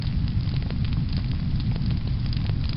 Звуки огнемёта
Пылающий инферно огнемет